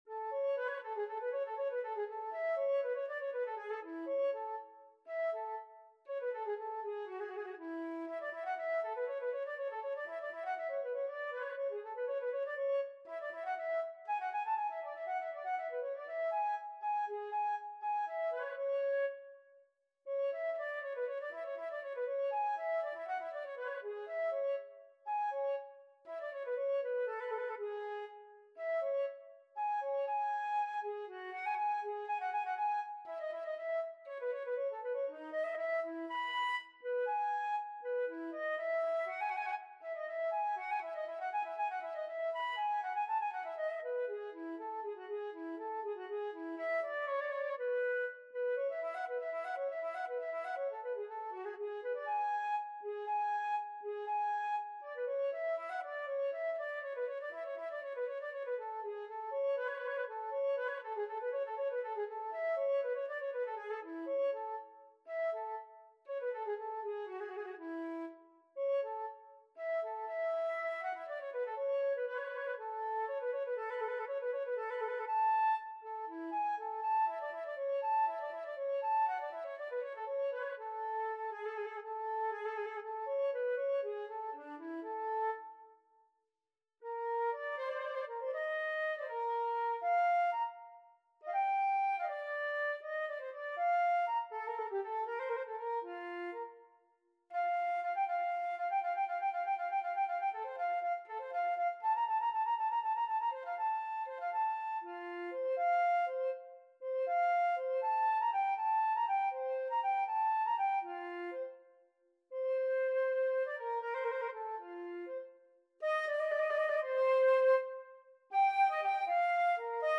Free Sheet music for Flute Duet
4/4 (View more 4/4 Music)
A major (Sounding Pitch) (View more A major Music for Flute Duet )
I: Vivace (View more music marked Vivace)
Flute Duet  (View more Intermediate Flute Duet Music)
Classical (View more Classical Flute Duet Music)